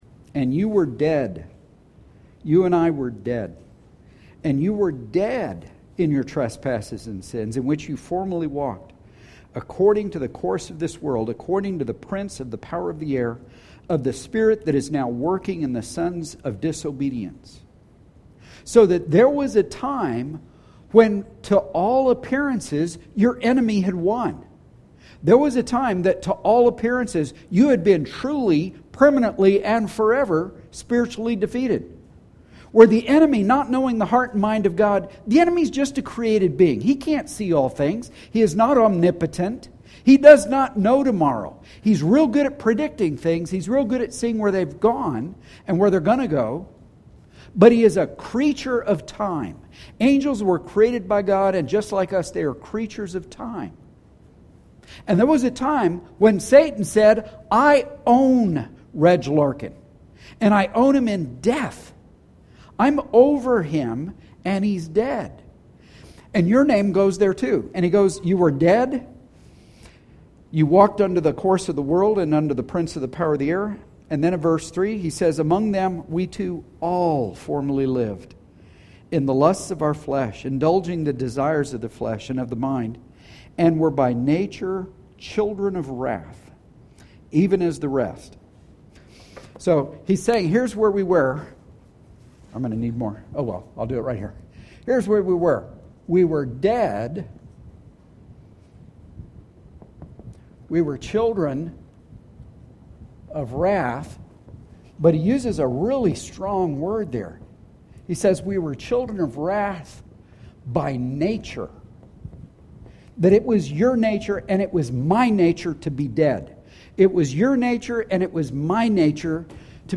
Sound team note: We had some technical difficulties with the recording device, so unfortunately we were unable to record the very beginning of the sermon. The recording starts about five minutes in.